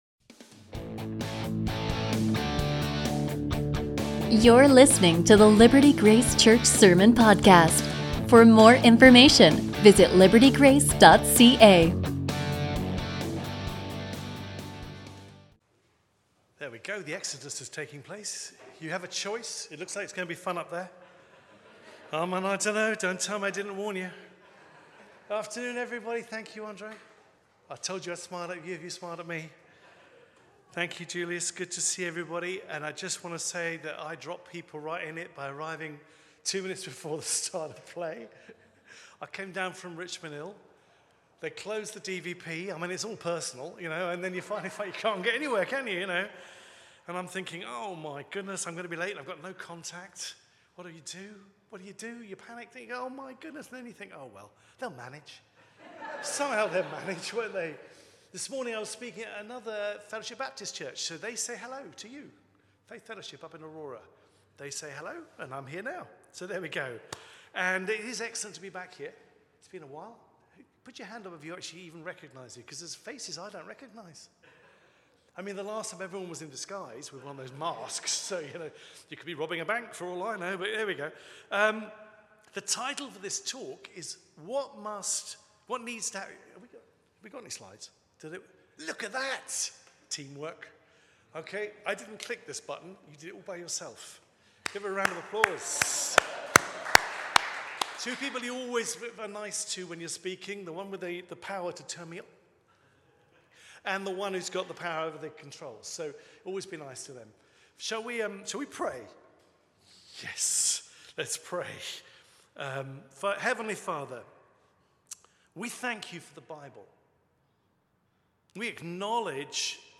A message from the series "Standalone."